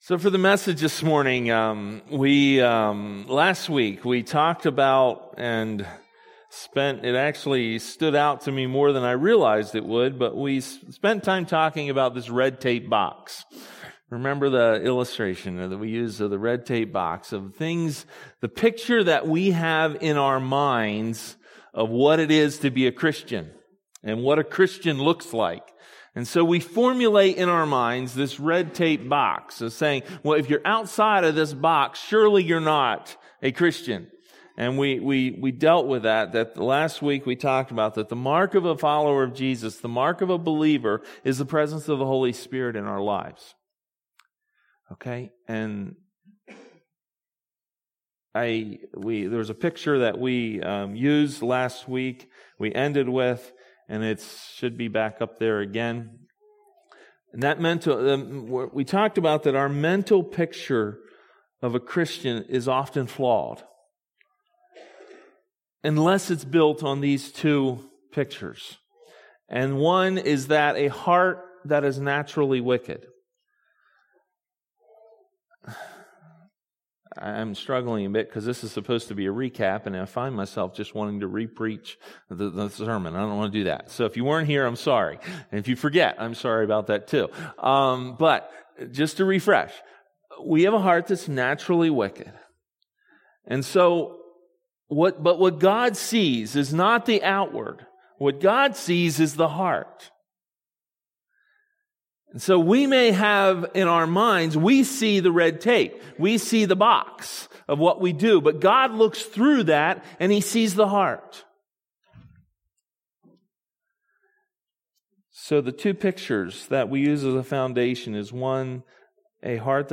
Maranatha Fellowship's Sunday Morning sermon recordings.